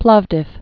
(plôvdĭf)